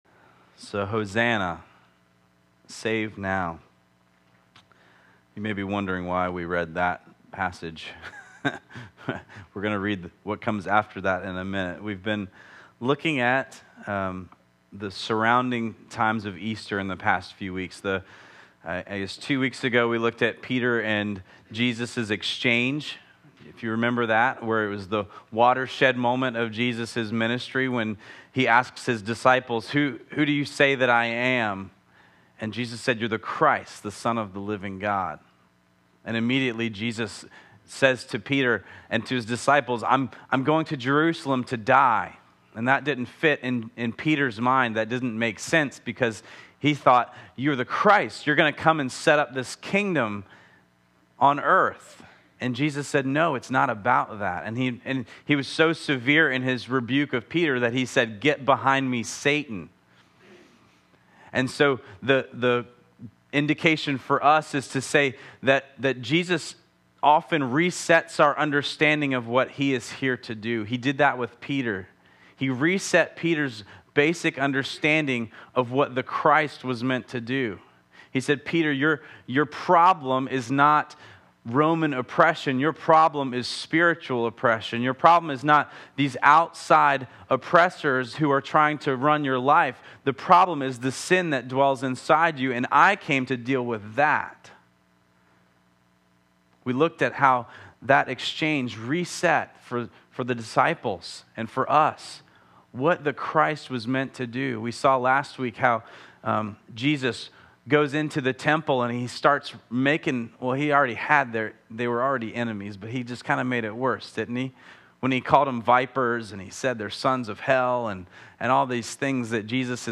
This week is another example where my notes are fairly minimal, but I filled things out much more in the sermon.